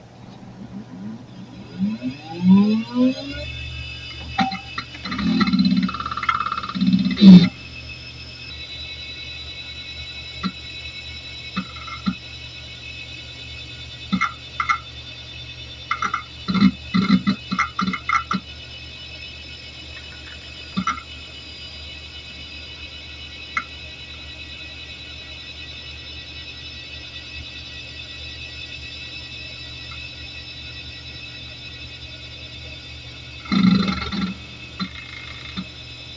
Anyway, here is a nice WD Caviar 2635 booting up, and doing a SNOOPER disk benchmark at the end.